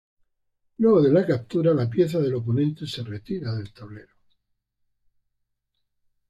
Pronounced as (IPA) /taˈbleɾo/